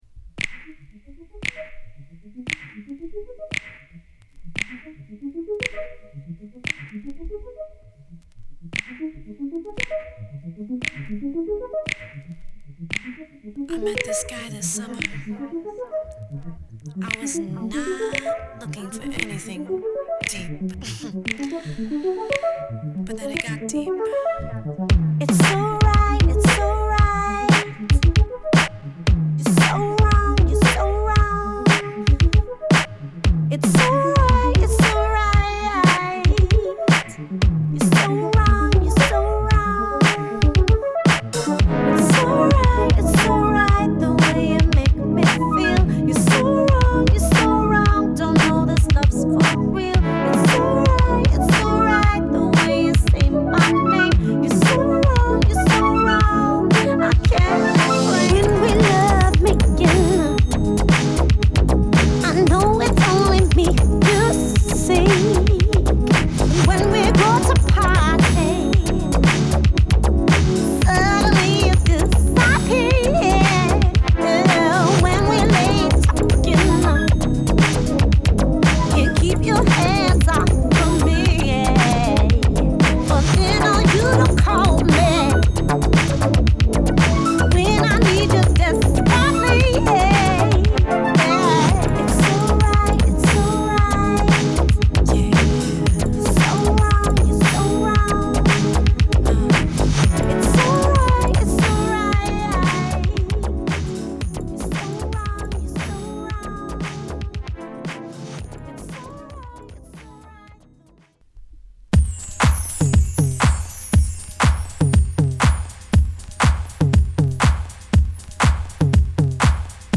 ベースメント・アフターパティー・ブギー！
軽快なビートダウン／ハウスともリンク出来る、アルペジオシンセに声ネタを絡めたディスコブギー